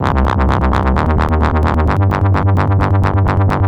Growling Moog C 130.wav